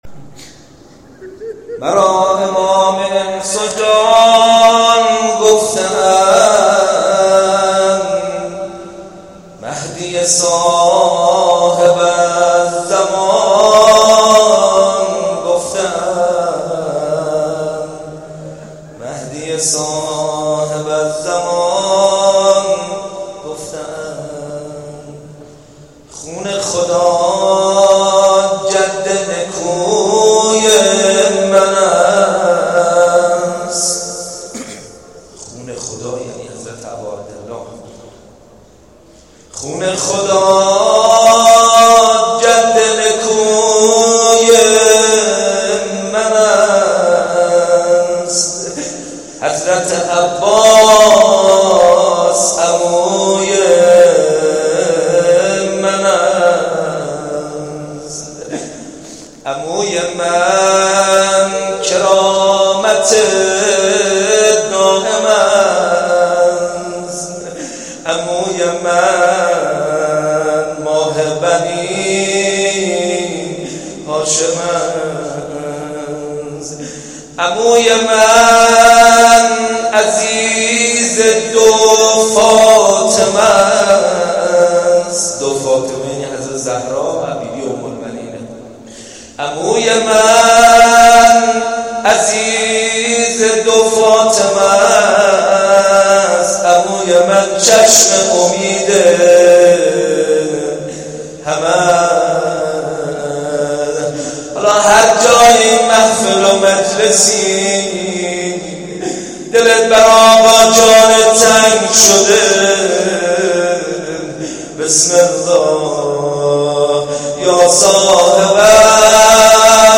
روضه حضرت عباس (س) روز تاسوعا (حرم).mp3
روضه-حضرت-عباس-س-روز-تاسوعا-حرم.mp3